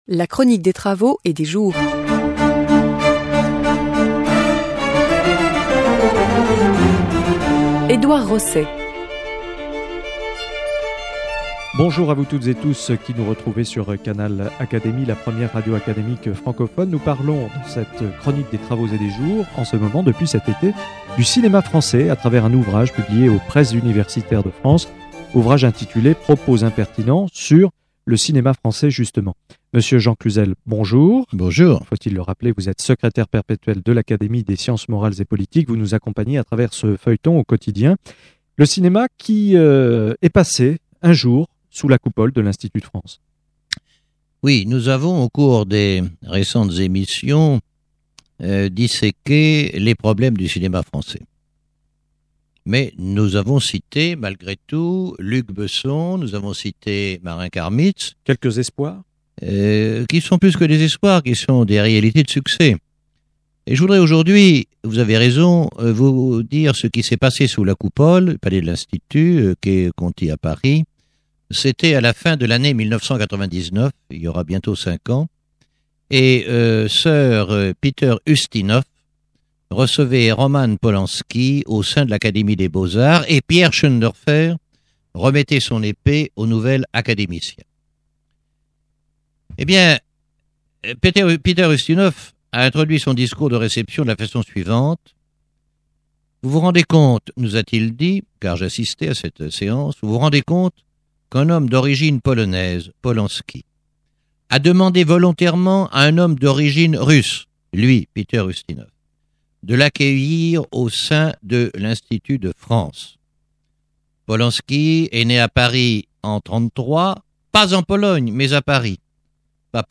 par M. Jean Cluzel, secrétaire perpétuel de l’Académie des sciences morales et politiques